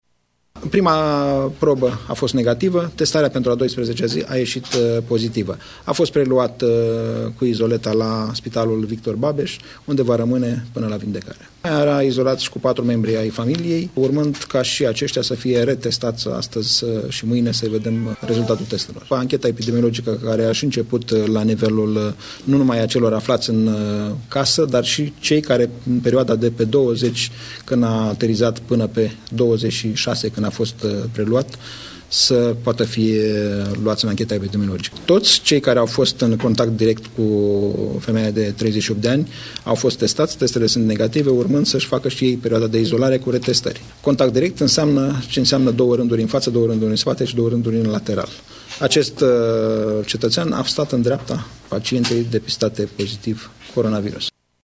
Secretarul de stat în Ministerul Sănătății, Nelu Tătaru, a susținut în urmă cu puțin timp o conferință de presă în care a venit cu mai multe informații: